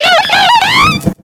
Cri de Chimpenfeu dans Pokémon X et Y.